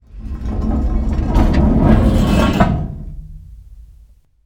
heavy-sliding-close.ogg